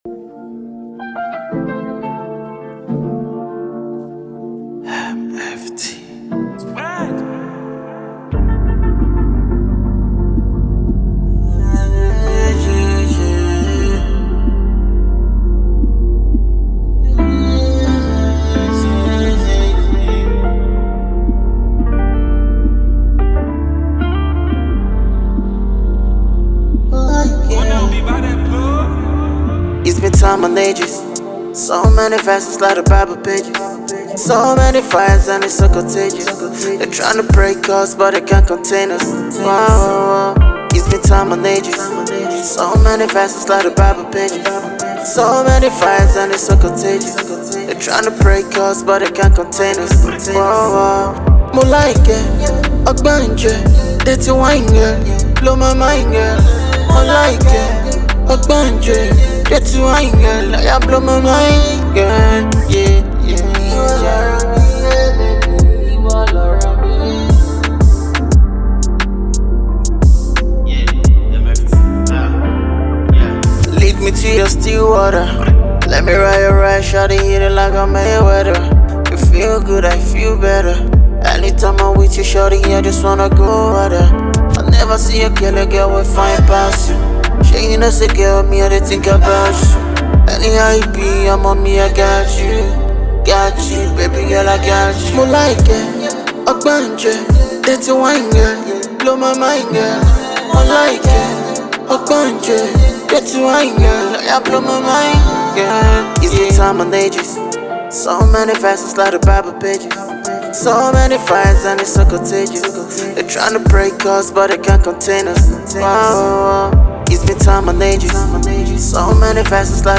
Fans of contemporary Afro-street music should not miss this.